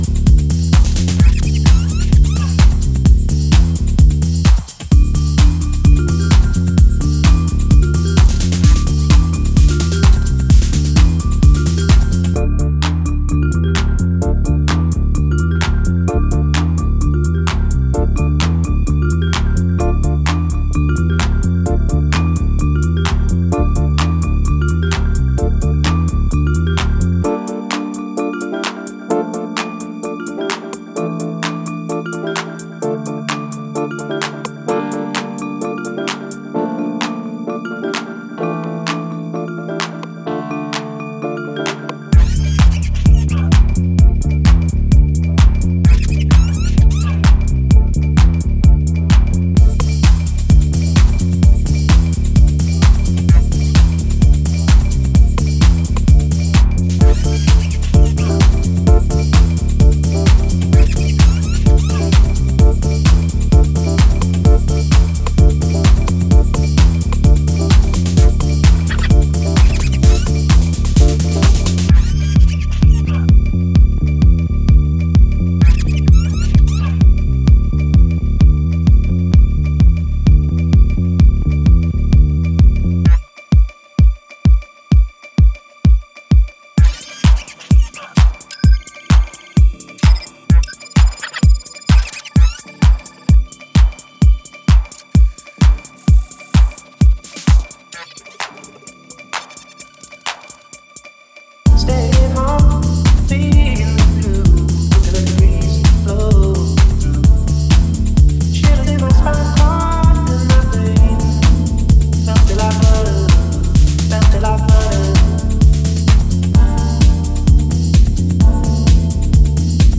迫り来るベースラインとマリンバ/ローズピアノの軽やかな音色が対比するB-1も非常に楽観的な響きです。